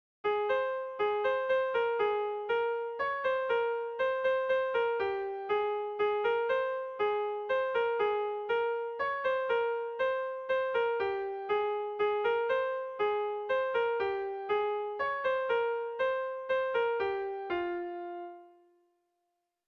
Dantzakoa
Fandango, trikitia.